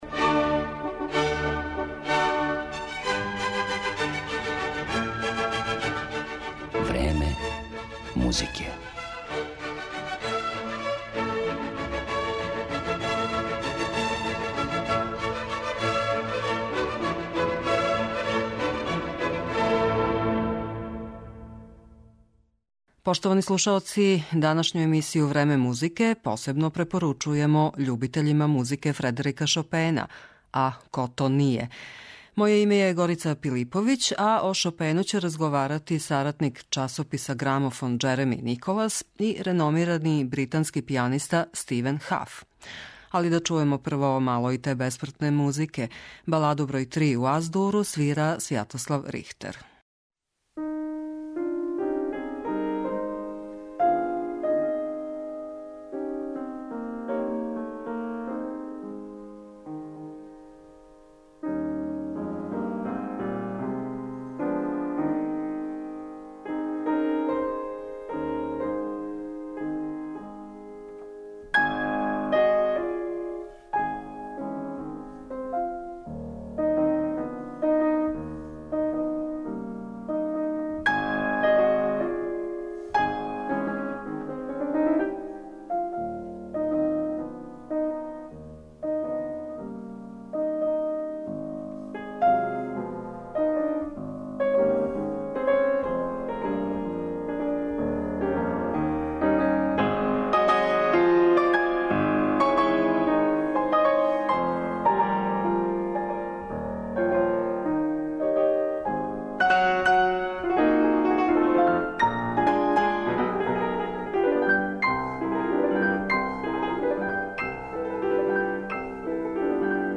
Преносимо разговор из часописа 'Грамофон', са пијанистом Стивеном Хафом.